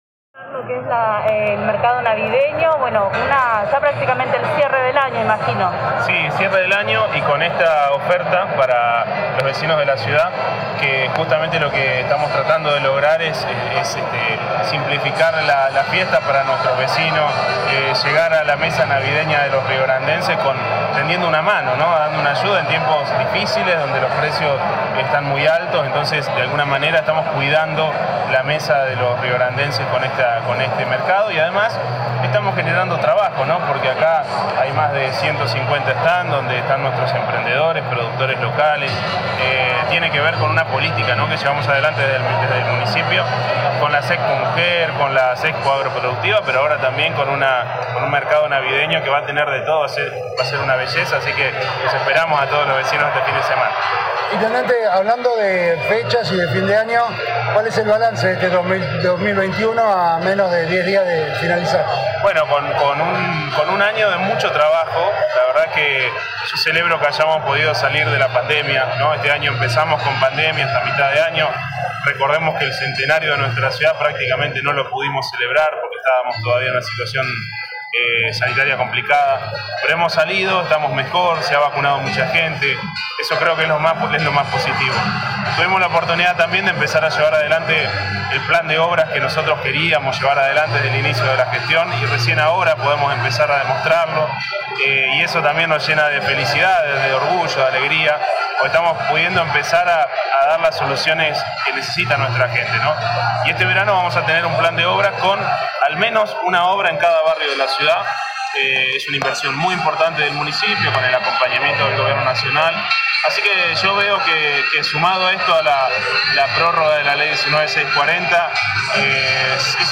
En dialogo con este medio el jefe comunal sostuvo que se trata de llegar a la mesa de todos los riograndenses y de generar trabajo ya que hay mas de 150 stands.